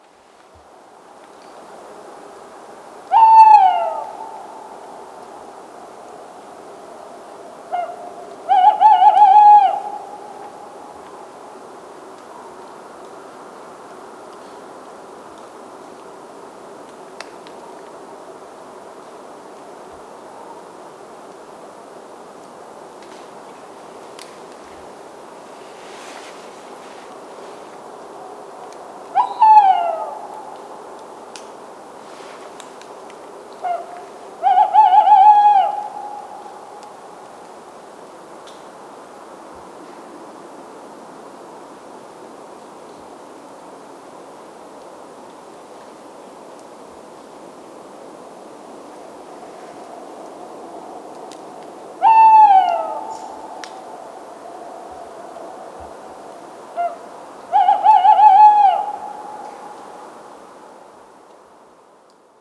1. Red-Tailed Hawk (Buteo jamaicensis)
• Call: A raspy, descending scream: